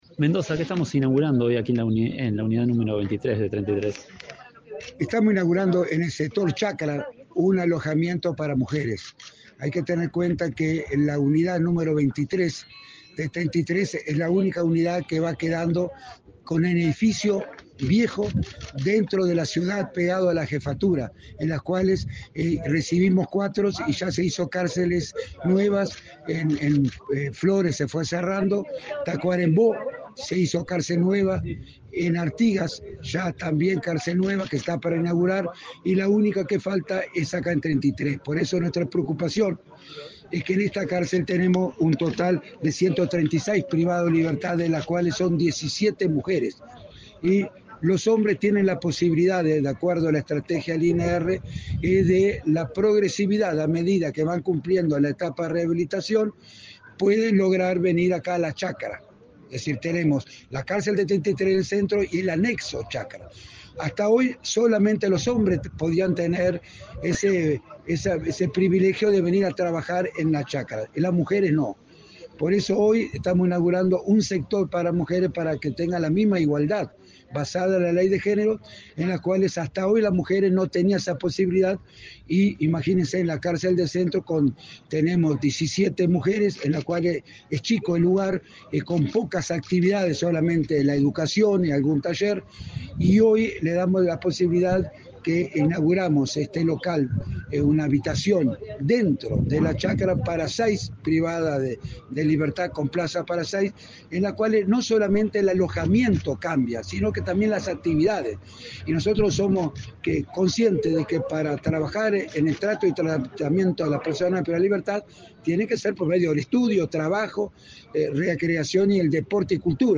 Entrevista con el director del INR, Luis Mendoza
Tras el evento, Mendoza realizó declaraciones a Comunicación Presidencial.